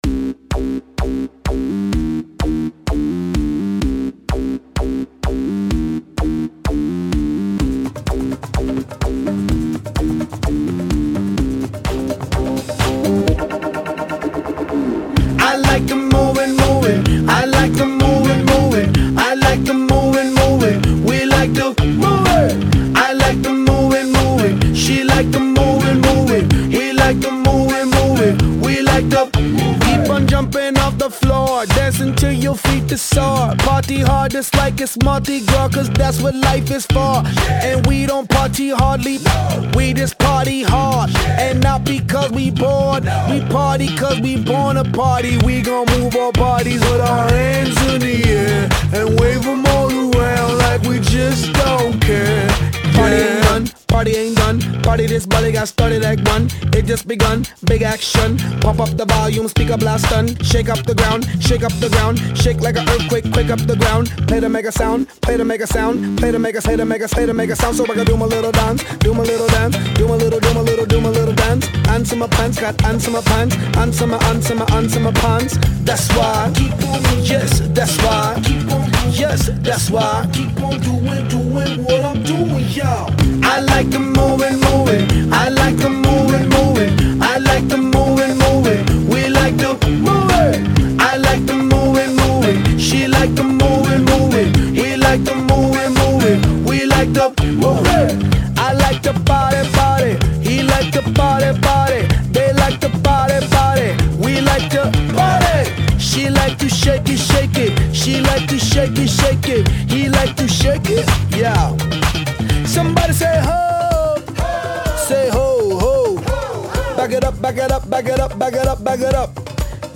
دارای آوازهای راگا توسط خواننده رپ ترینیداد و توباگو